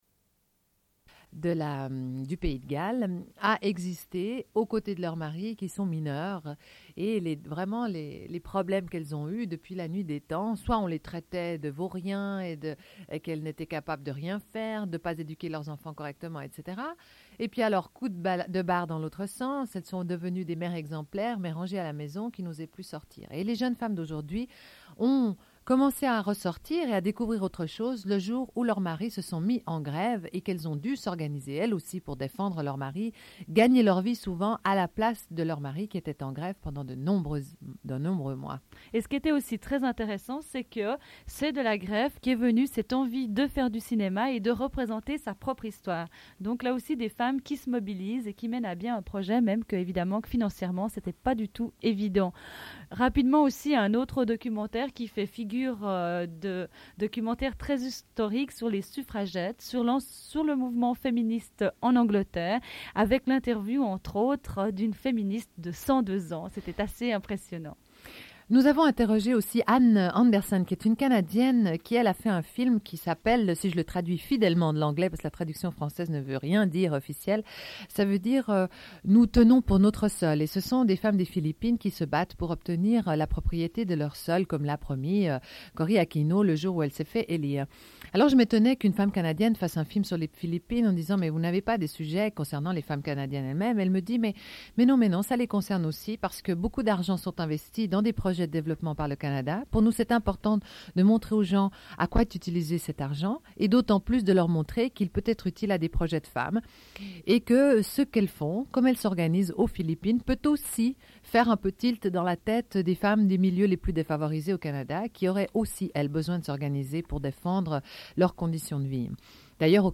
Une cassette audio, face B00:47:29